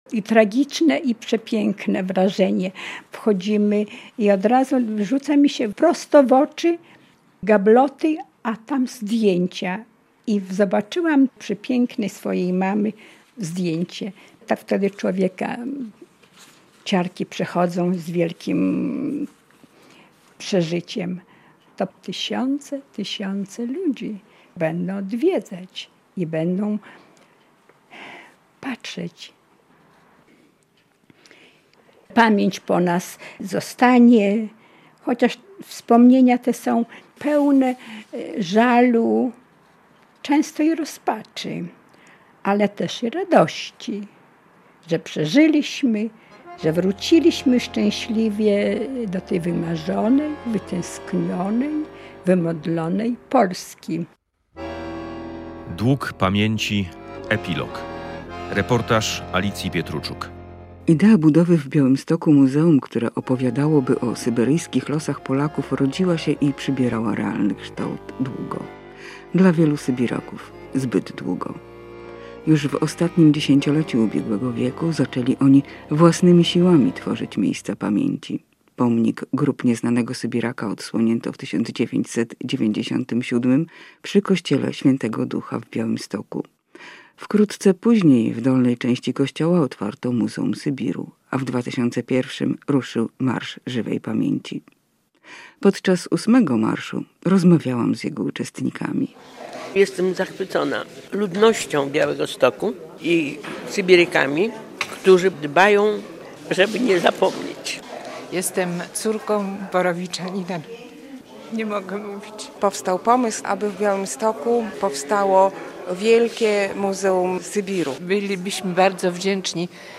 Radio Białystok | Reportaż | "Dług pamięci.